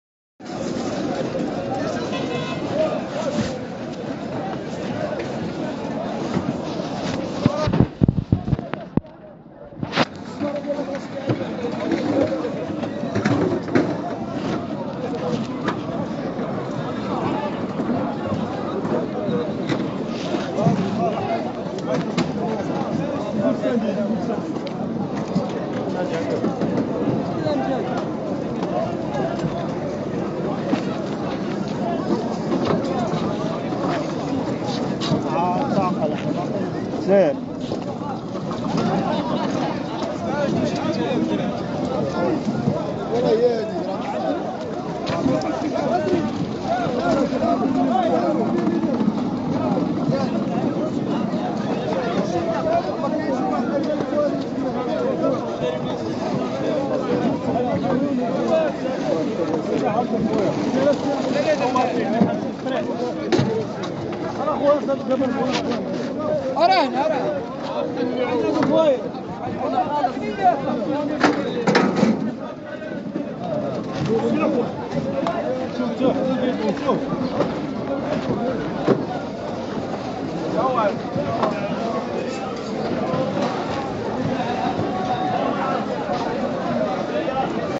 Les cris des mouettes, très nombreuses, d'abord. Ensuite, le brouhaha des vendeurs, transporteurs, colporteurs, acheteurs… encore plus nombreux que les mouettes.
Ambiance-sonore-2-marche-de-gros-de-poisson-de-Casablanca-premier-jur-de-Ramadan-2025-converti.mp3